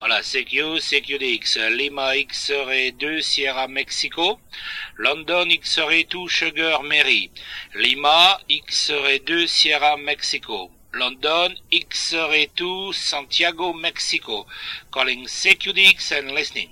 The TW-232S is a base station dynamic (even if somes said its a ceramic insert) microphone with amplifier and speech compression.
Frequency response : 200Hz - 5kHz
My opinion, a good microphone which does the job, silent pedal switch, full metal jacket, reliable, compression could have been stronger, more low tones could have been fine.